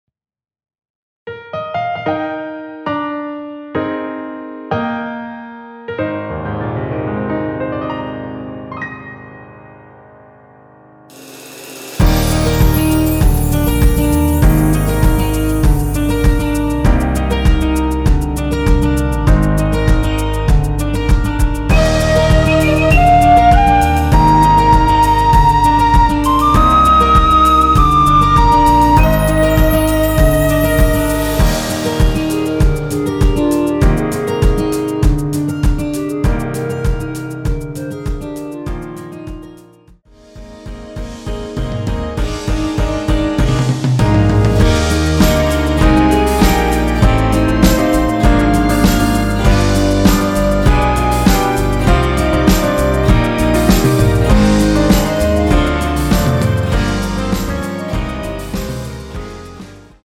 원키에서(-5)내린 멜로디 포함된 MR입니다.(미리듣기 확인)
Eb
앞부분30초, 뒷부분30초씩 편집해서 올려 드리고 있습니다.
중간에 음이 끈어지고 다시 나오는 이유는